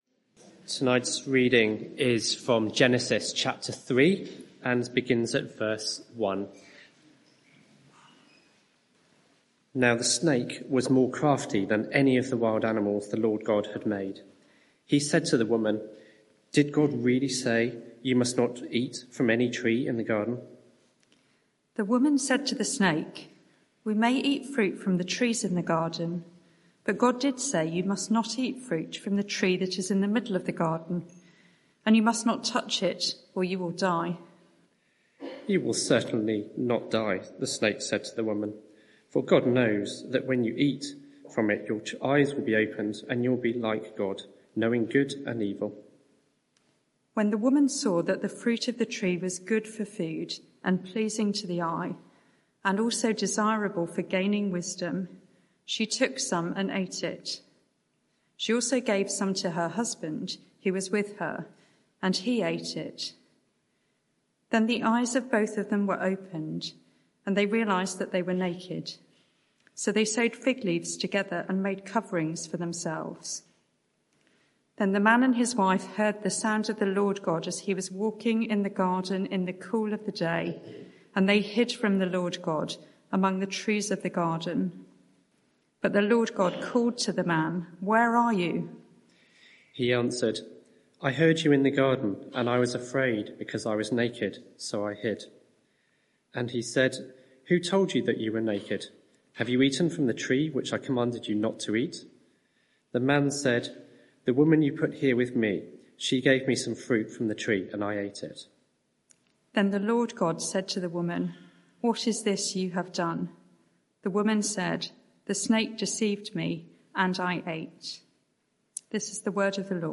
Media for 6:30pm Service on Sun 03rd Nov 2024 18:30 Speaker
Sermon Search the media library There are recordings here going back several years.